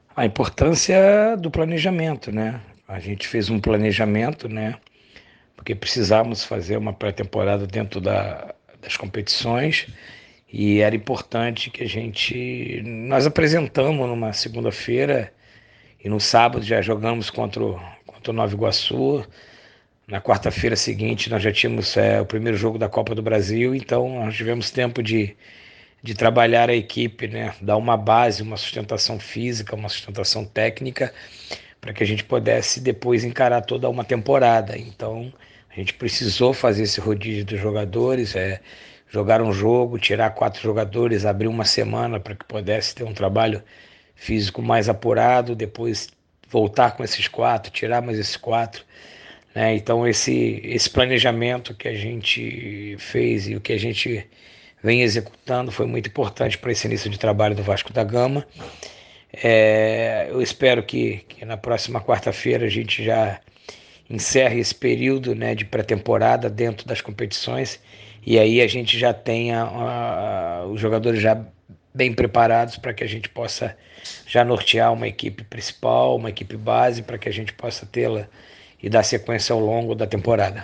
Em entrevista exclusiva à Super Rádio Tupi, treinador comemorou o acerto do planejamento no início da temporada